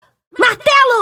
martelos.mp3